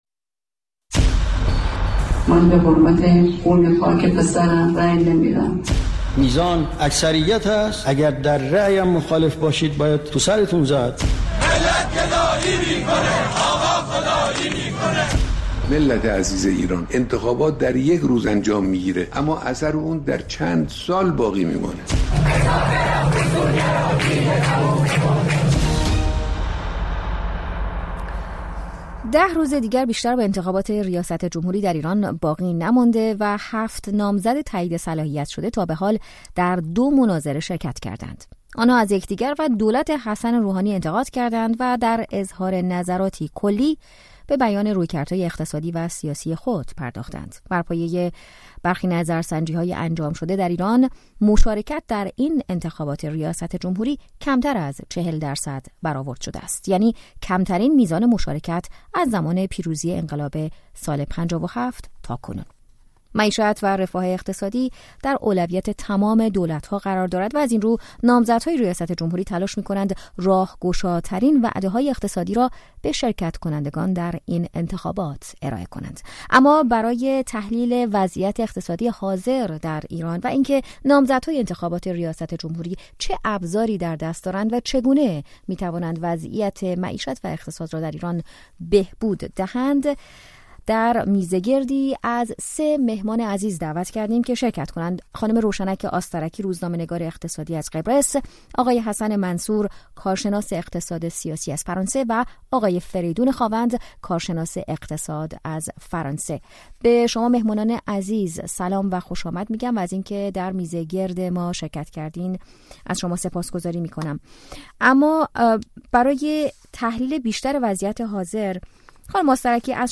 میزگرد ویژه انتخابات: انتخابات و اقتصاد